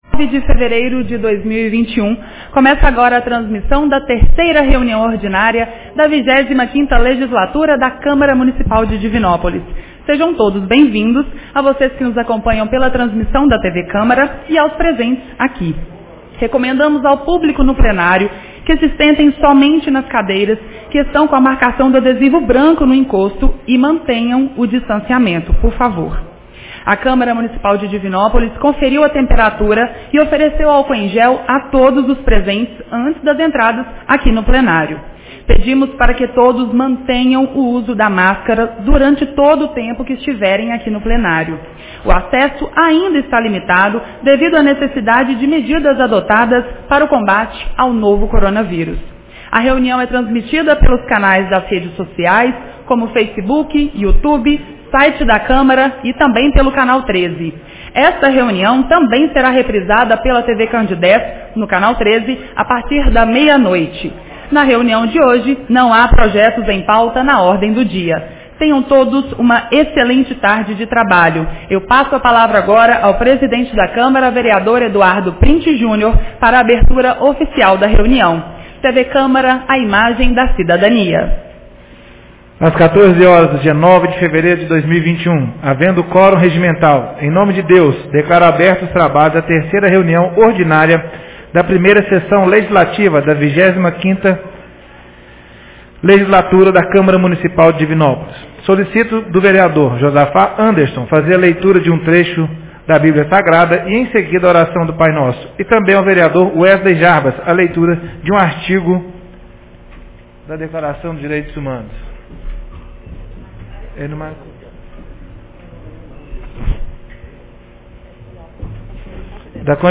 Reunião Ordinária 03 de 09 fevereiro 2021